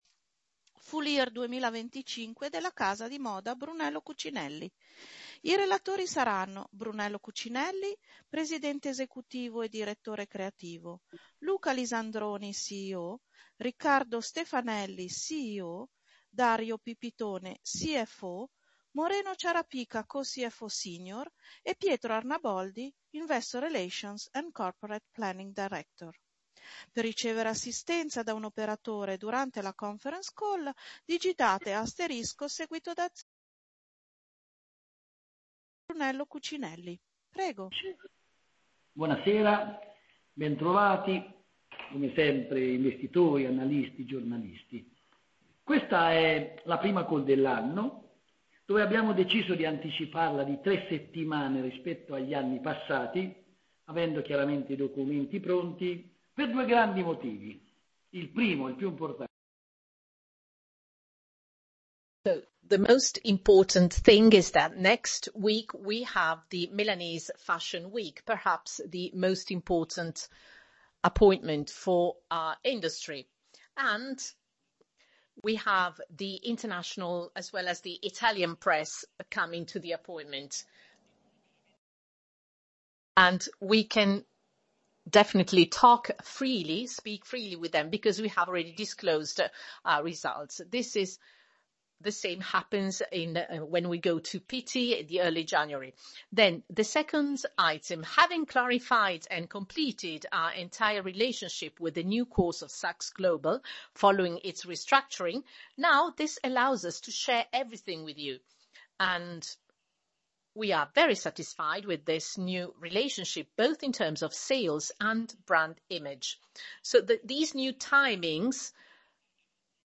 Conference_call_FY_2025_Results.mp3